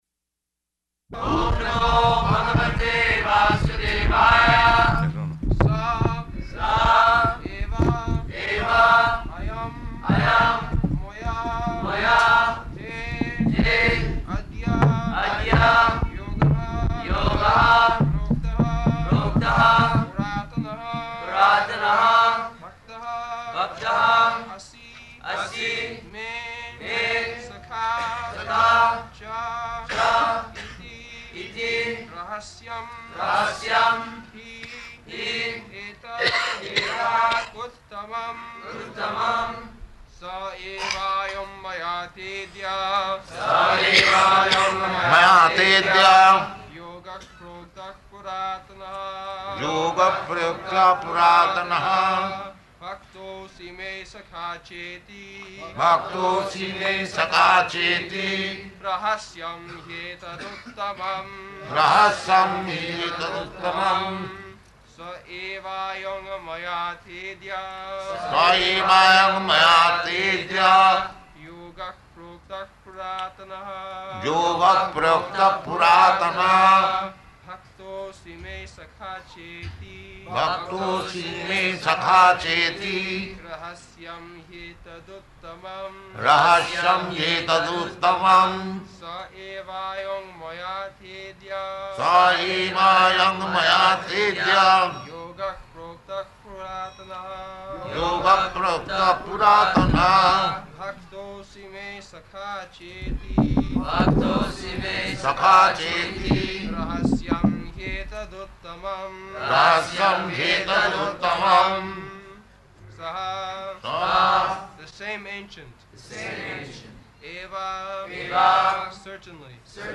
March 23rd 1974 Location: Bombay Audio file
[Prabhupāda and devotees repeat] [leads chanting of verse, etc.]